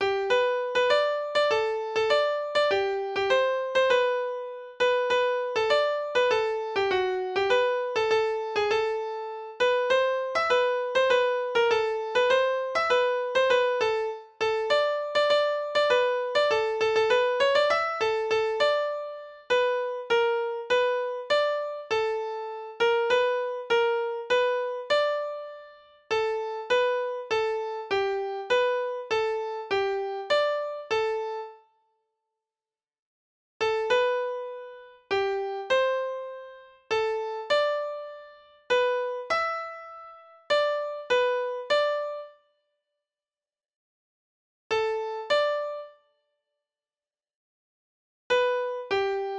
Origin Music Hall